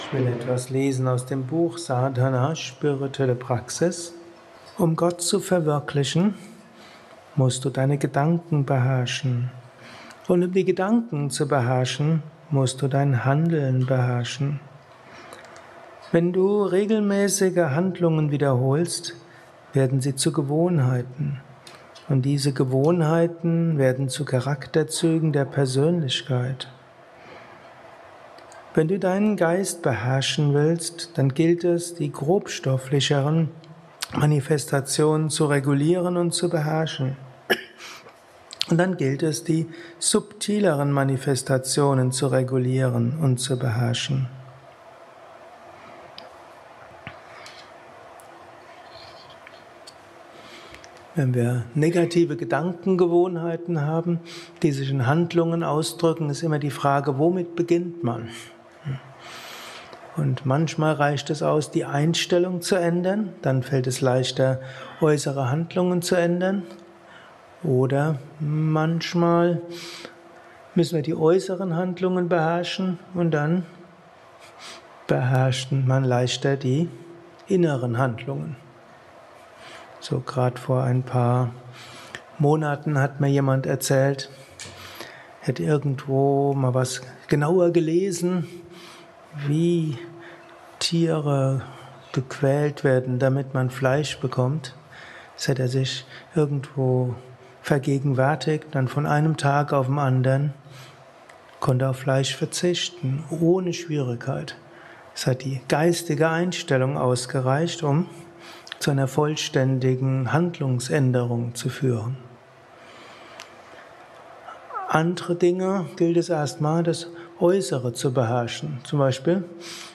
Dies ist ein kurzer Vortrag als Inspiration für den heutigen Tag